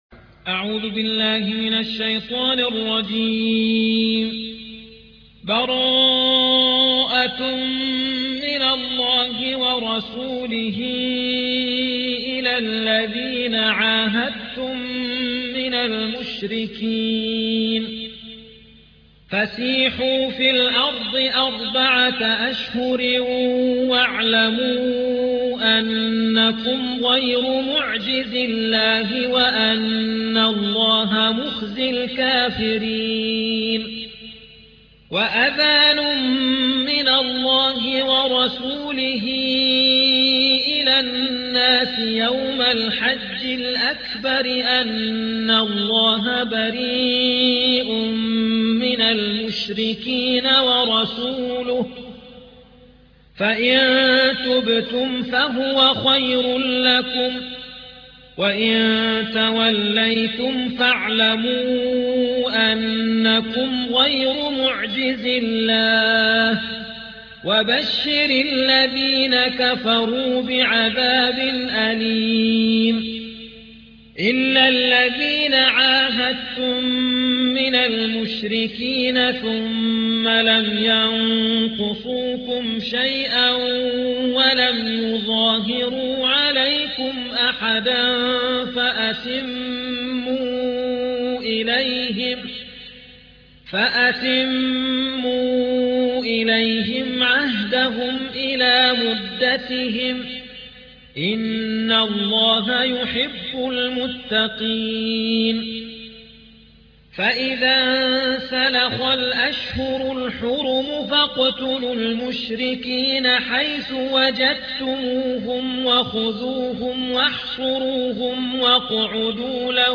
9. سورة التوبة / القارئ